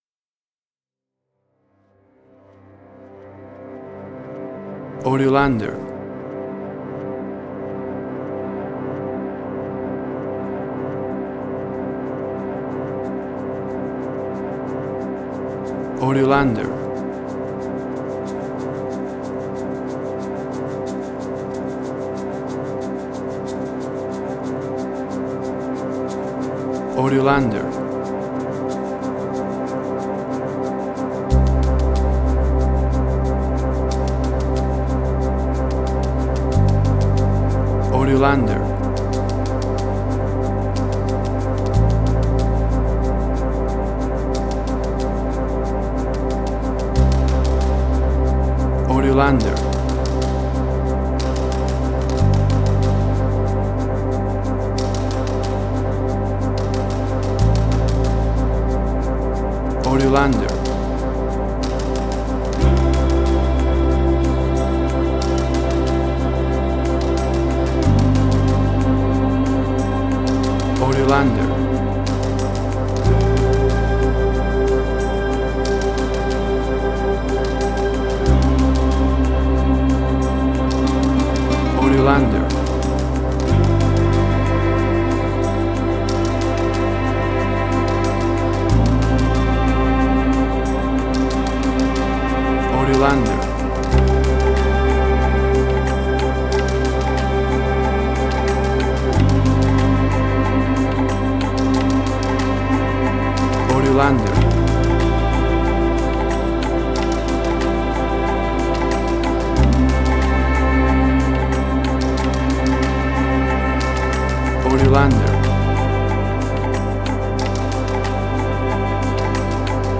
Latin Drama_Similar_Narcos, calm, space, relax.
Tempo (BPM): 92